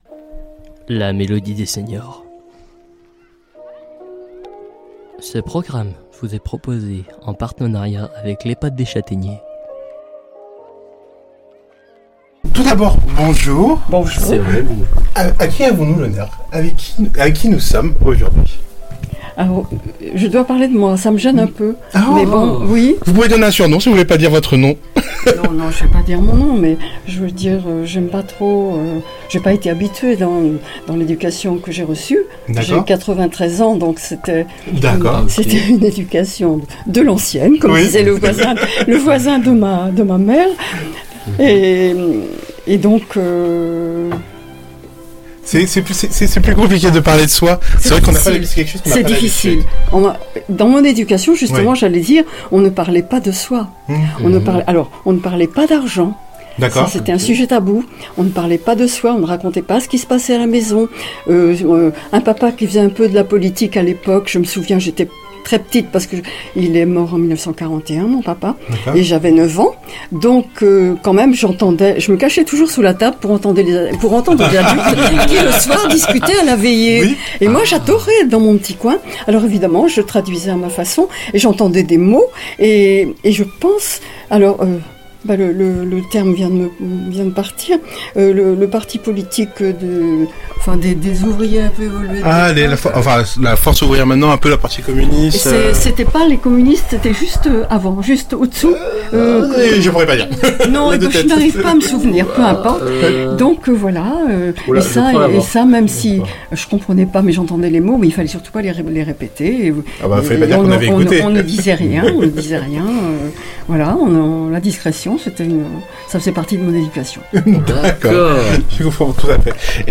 Une rencontre en musique des habitants de l’ehpad des chataigners.